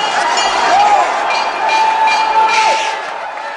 • RACECOURSE BELL.wav
RACECOURSE_BELL_DER.wav